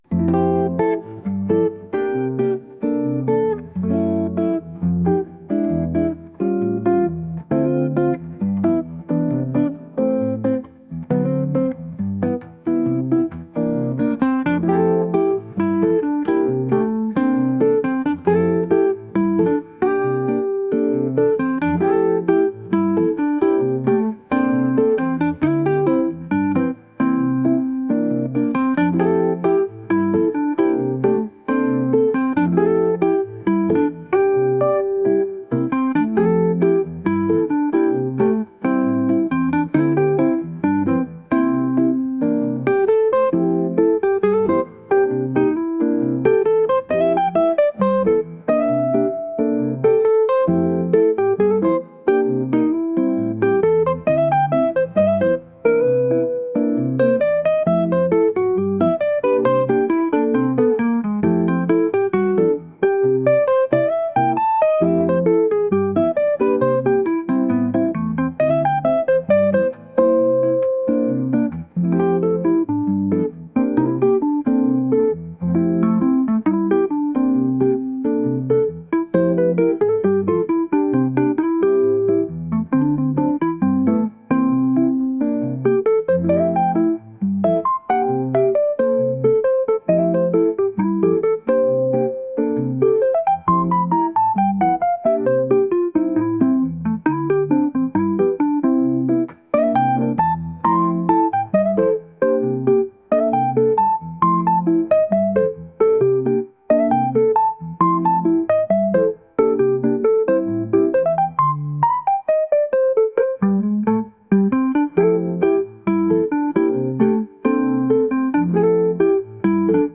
週末にのんびりまどろむようなゆったりしたボサノバ曲です。